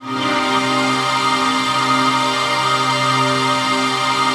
TRANCPAD26-LR.wav